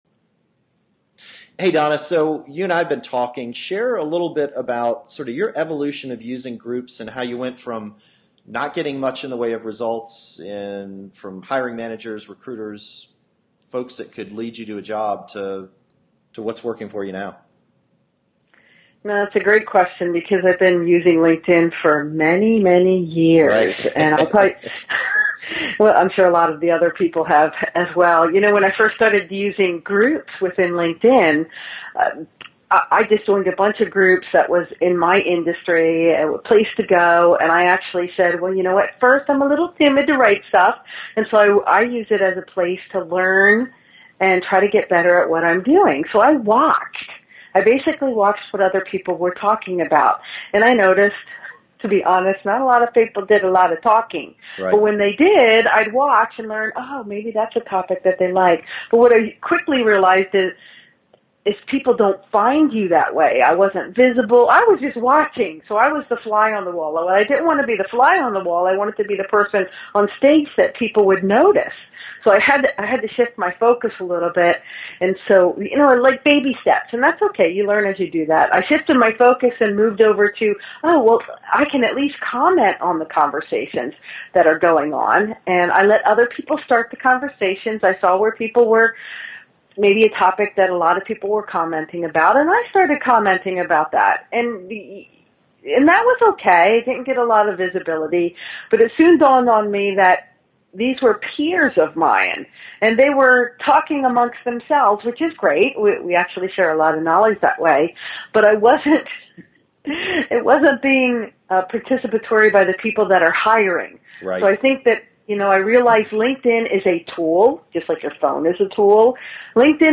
Interview, Job Search, Networking, Resume and LinkedIn